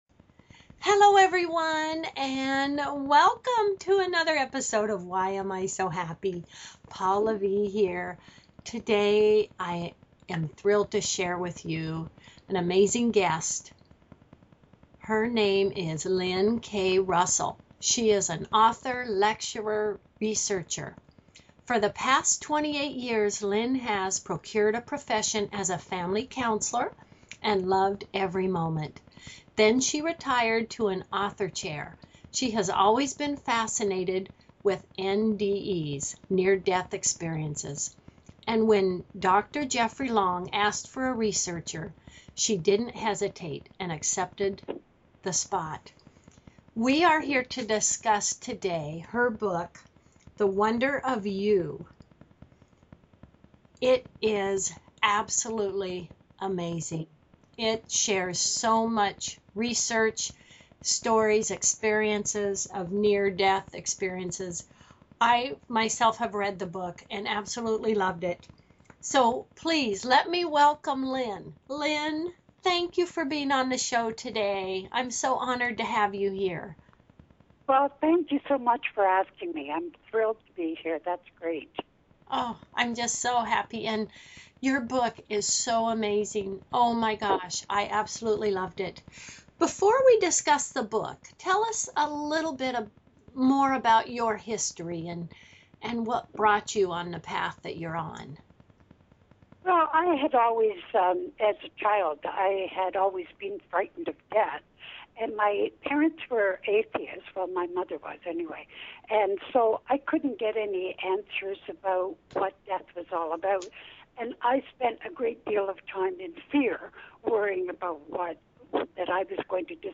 Talk Show Episode
Interviewing